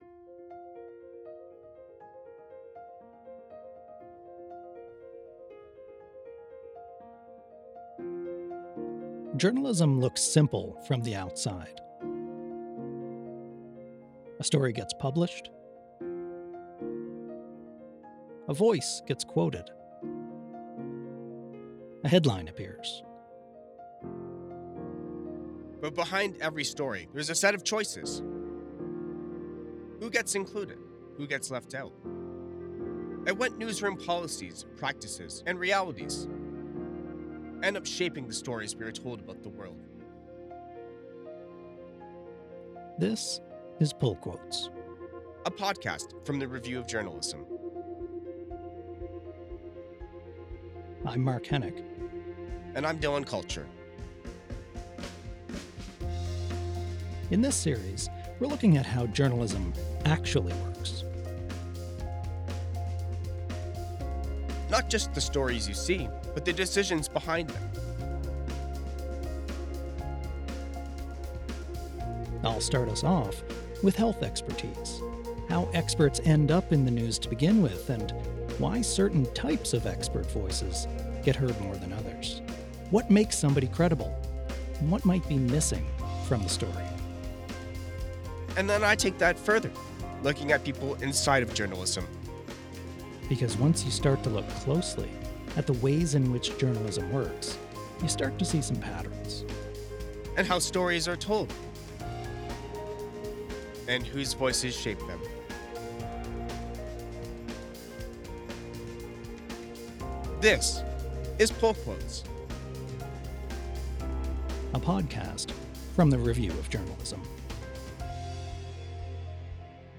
Music Credits